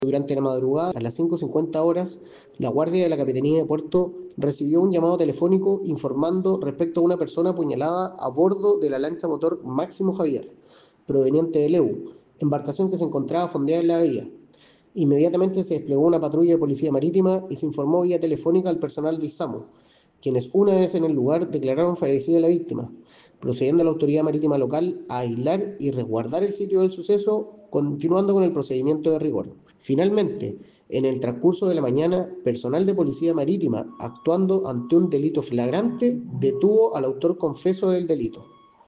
A su vez el capitán de Puerto de Quellón, Manuel Hidalgo Alfaro, puso de relieve el trabajo realizado a partir de la denuncia que se efectuó a la unidad naval en horas de la madrugada, en donde se constató la presencia de este cuerpo ya sin vida, despliegue de efectivos de la Armada que más tarde permitieron la detención de quien sería el autor de este hecho, el que fue puesto a disposición de las autoridades.